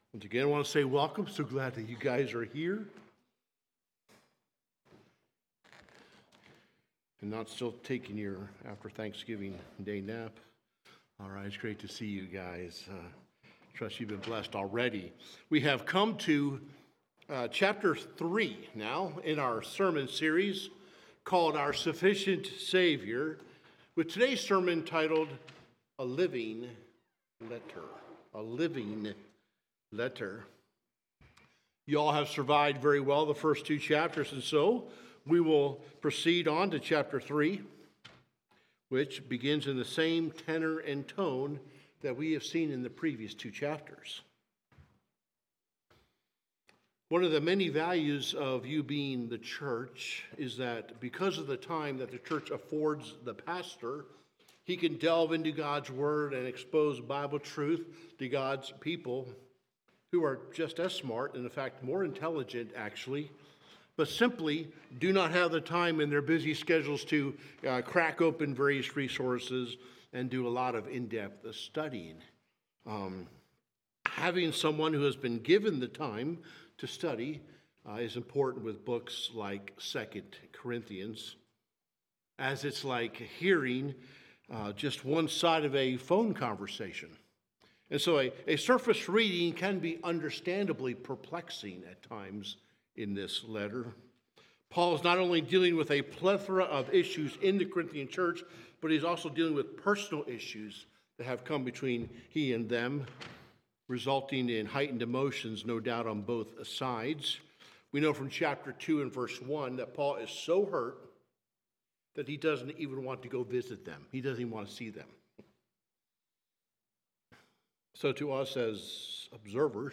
Sermons | Highland Baptist Church